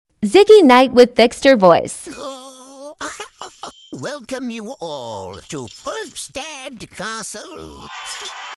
Ziggy knight with trickster voice sound effects free download